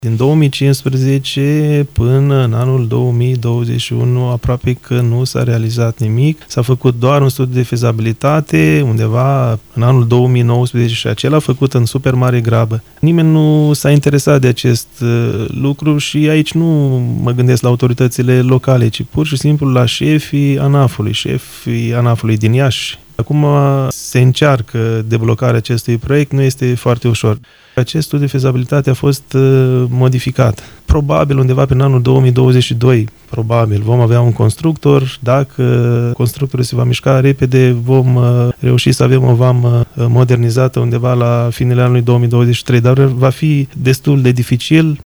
Într-un interviu acordat postului nostru de radio, POPOIU a declarat că deblocarea proiectului ar însemna crearea unor condiții moderne nu numai pentru angajați, ci și pentru cei care tranzitează frontiera de est a Uniunii Europene.